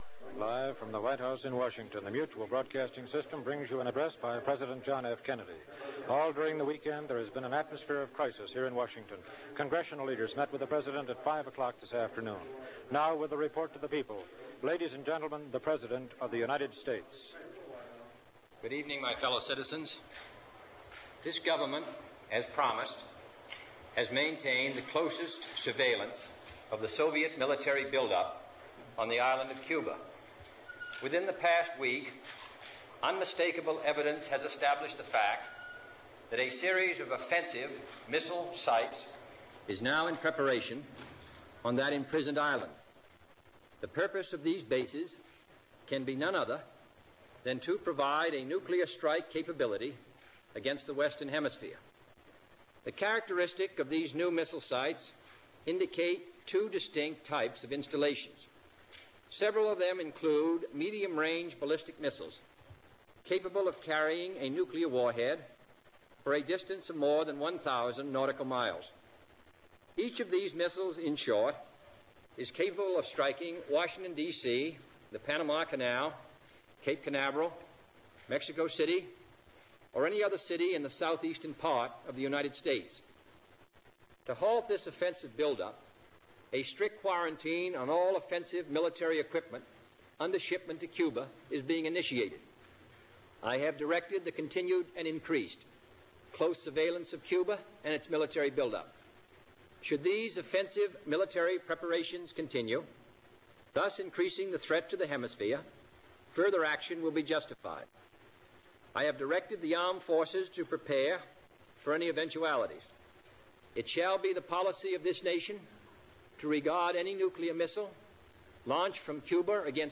Listen to President Kennedy present the Cuban Missle Crisis.